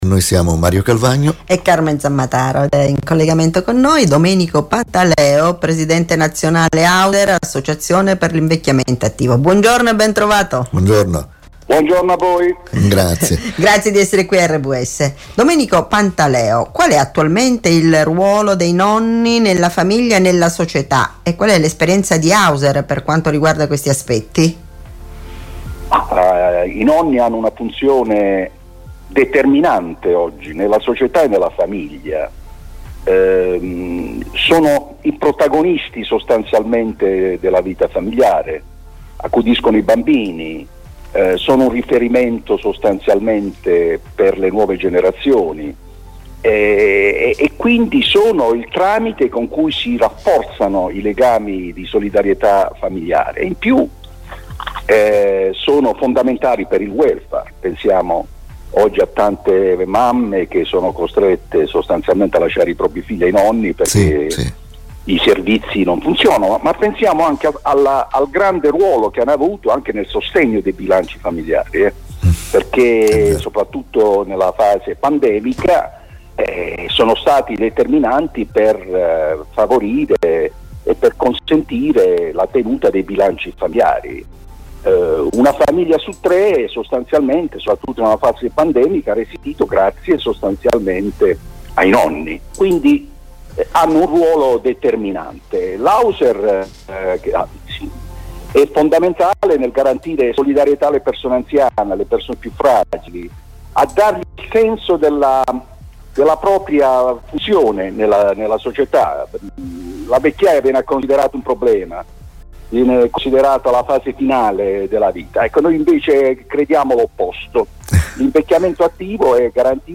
intervistano